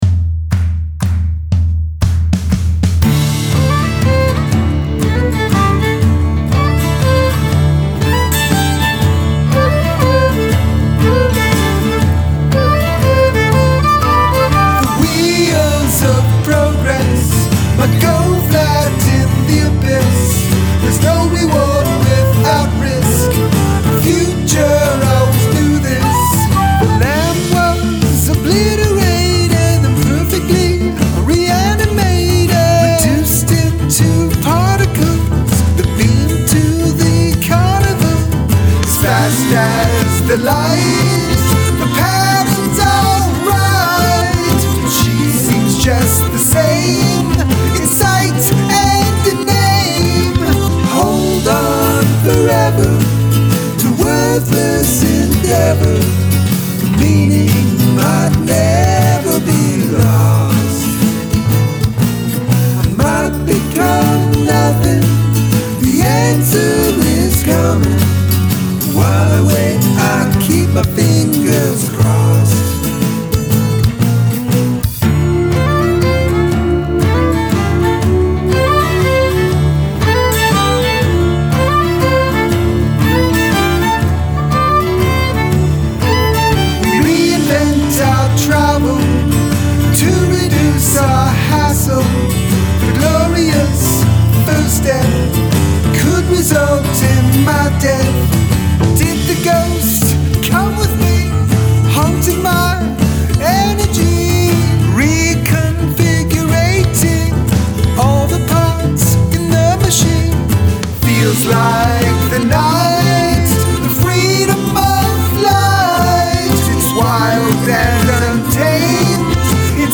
include a section of backwards singing or instrumentation